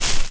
Game Footstep 2